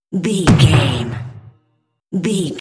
Cinematic stab hit trailer
Sound Effects
Atonal
heavy
intense
dark
aggressive
hits